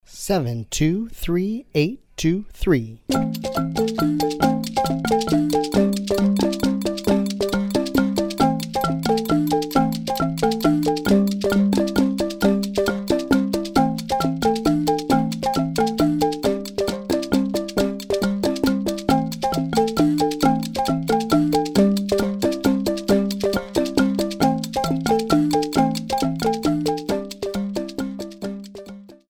The music combines various percussion instruments,
Medium Triple Meter
Medium Triple Meter - 135 bpm